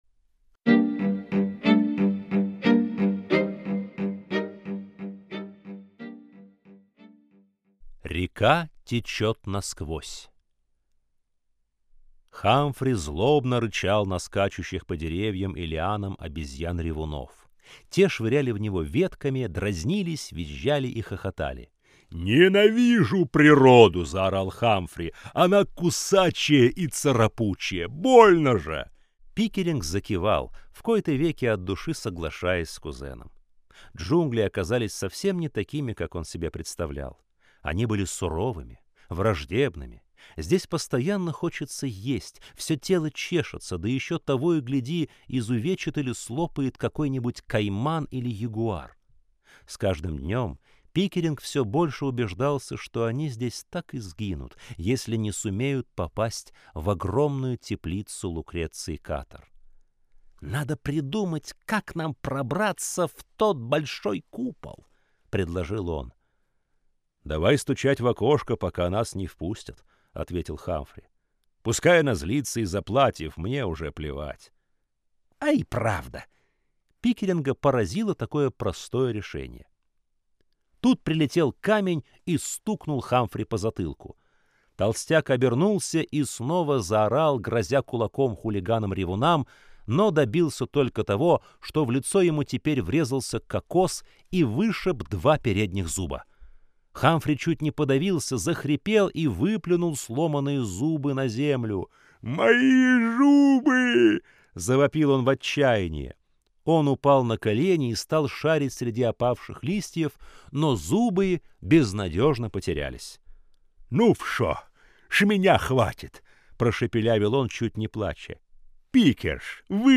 Аудиокнига Фабр. Битва жуков | Библиотека аудиокниг